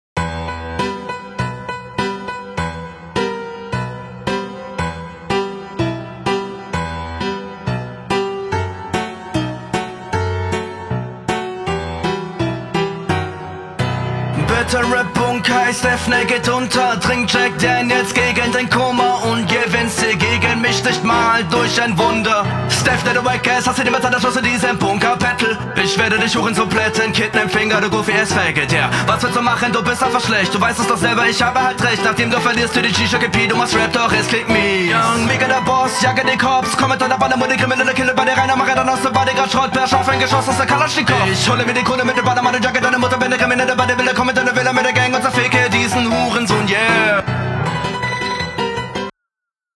Flowlich halt absolut krankes Ding, aber textlich leider so gar nichts.